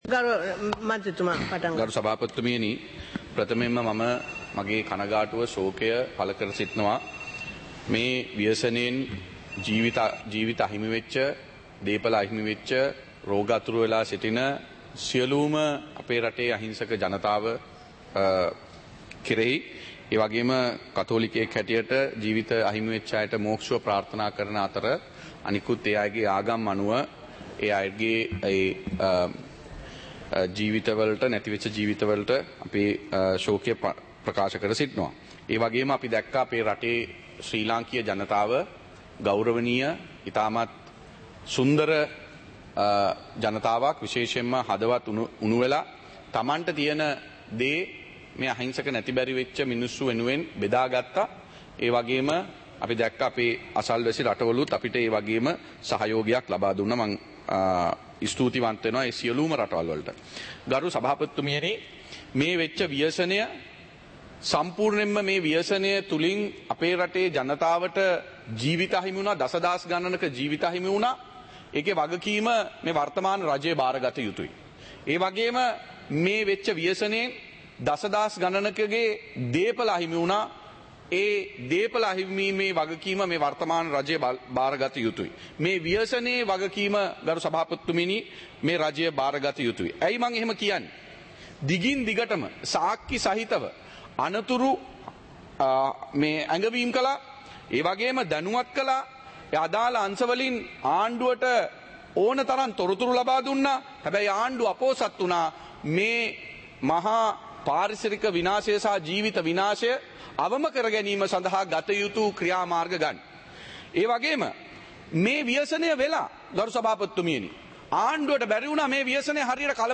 சபை நடவடிக்கைமுறை (2025-12-05)